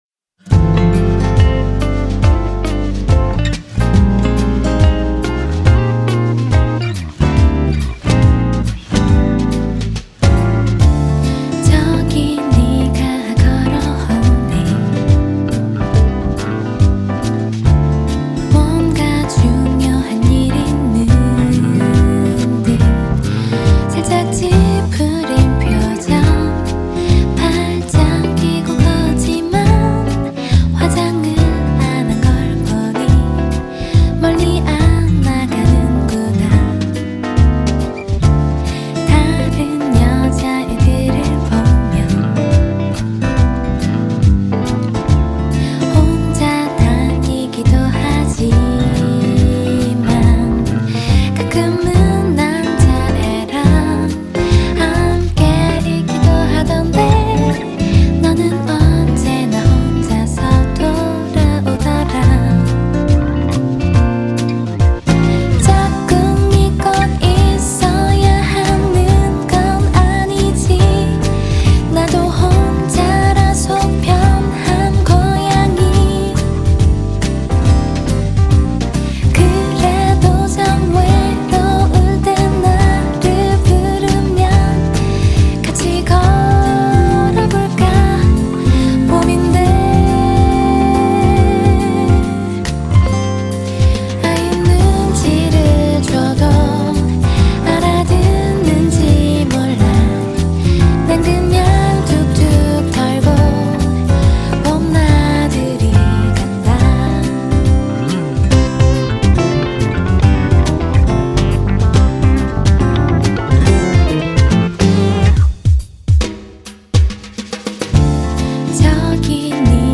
소편성으로 줄이고 줄였습니다.